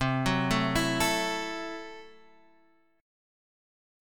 C6b5 Chord